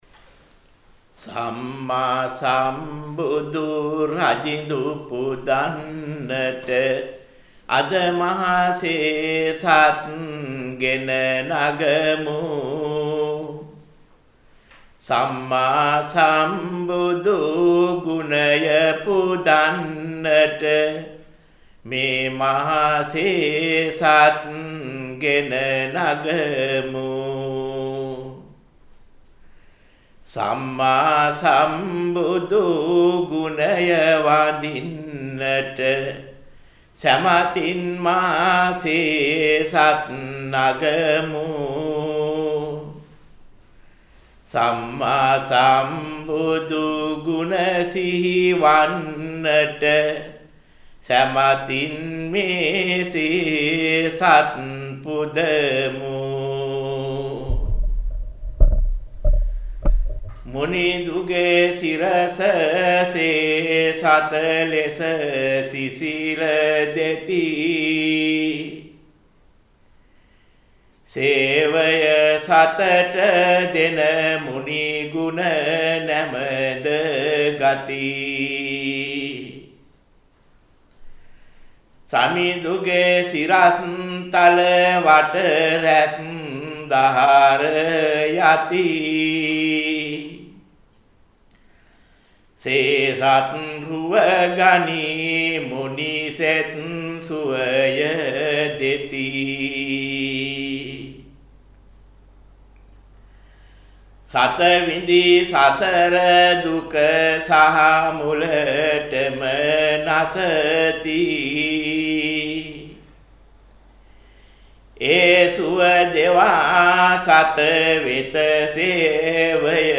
සේසත් පූජා පින්කම පෙරහැර පටිභාන කවි